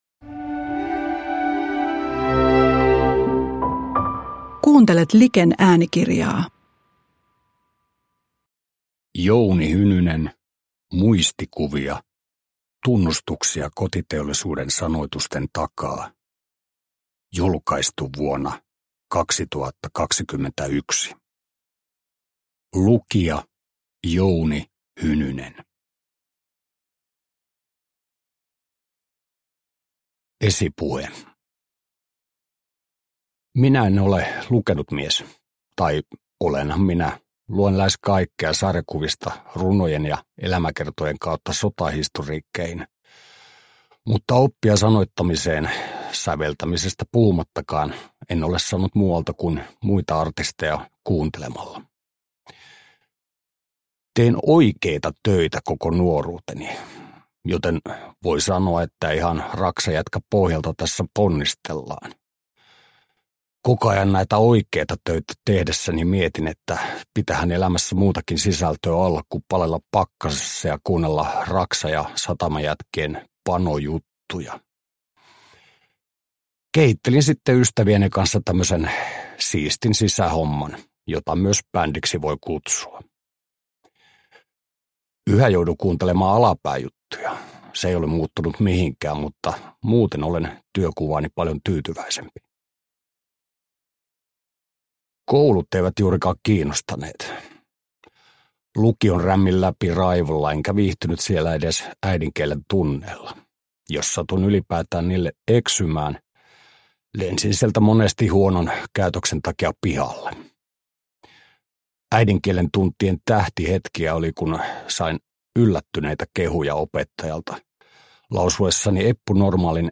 Uppläsare: Jouni Hynynen